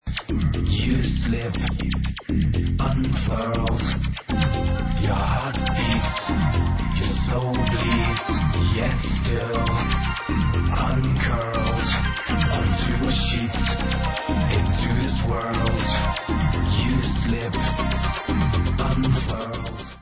Dance